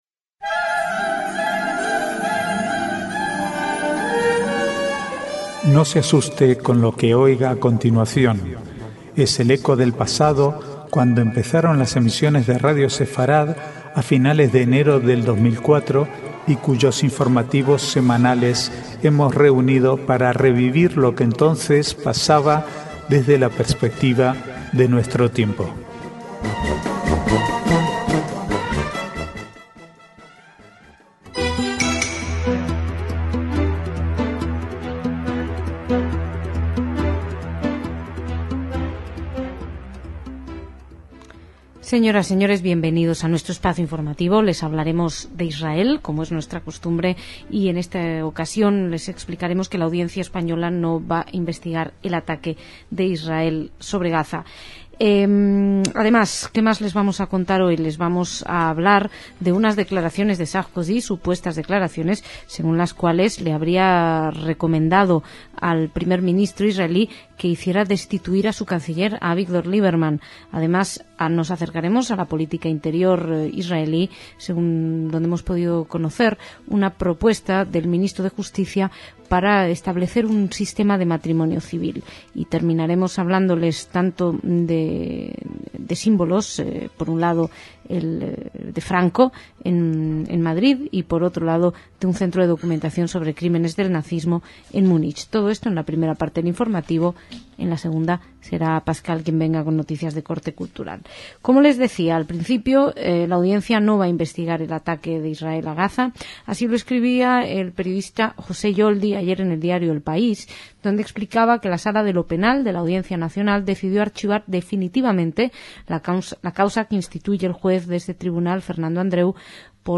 informativos semanales